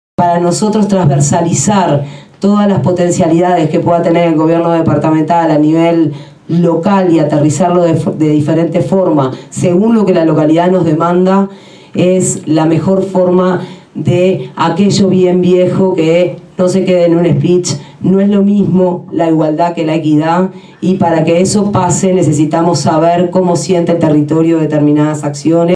Todos por la Educación: se realizó asamblea sobre propuestas educativas en Nicolich